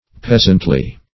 Peasantly \Peas"ant*ly\, a.